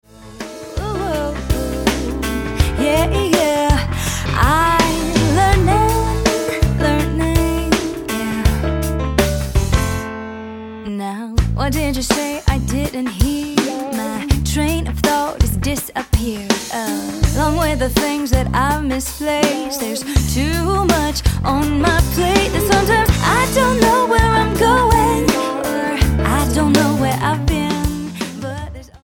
Pop Album
Style: Pop